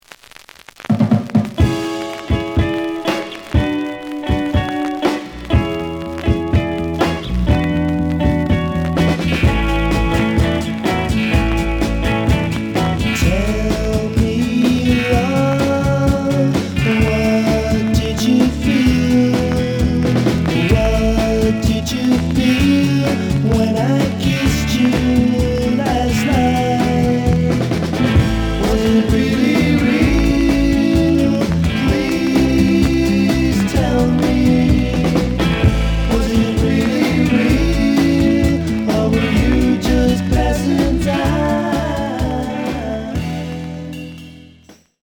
●Genre: Rock / Pop